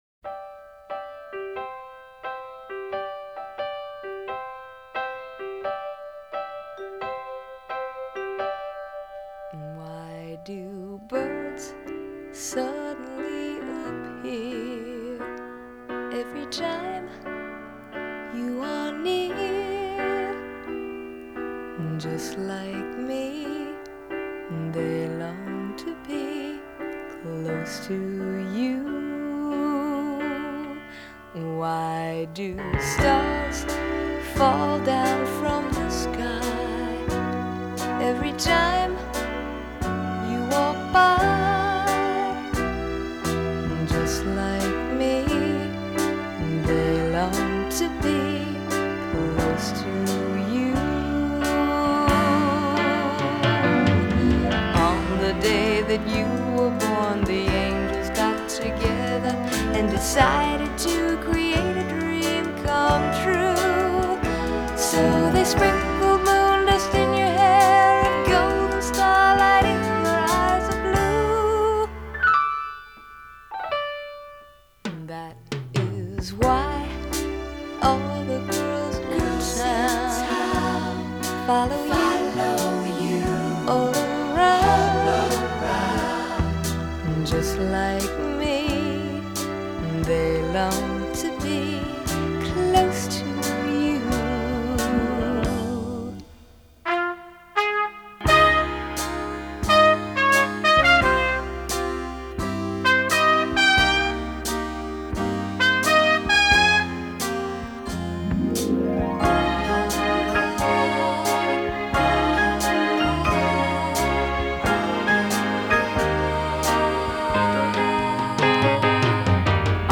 американский поп-дуэт
вокал и барабаны
фортепиано
Уверенный, похожий на колокольчик голос